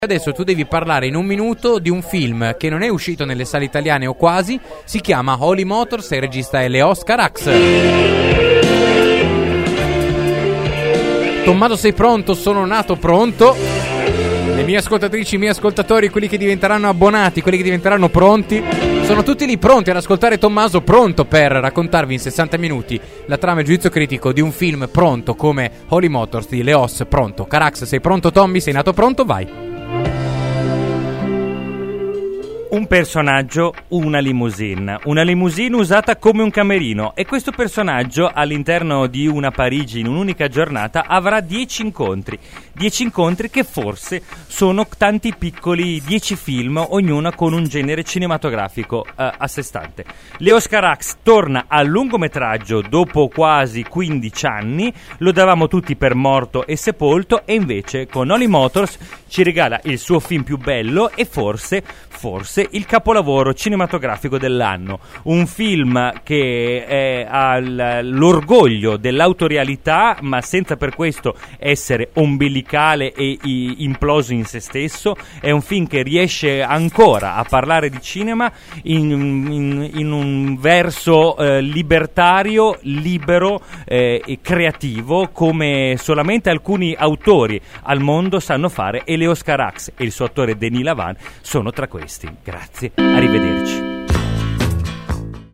“Dammi solo un minuto” è una rubrica di Seconda Visione. Durante la puntata chiediamo agli spettatori di scegliere (via mail o sms) uno dei conduttori per fargli riassumere in un minuto trama e giudizio di uno dei film in scaletta.